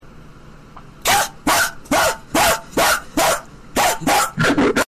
IShowSpeed Barking Sound Effect Free Download
IShowSpeed Barking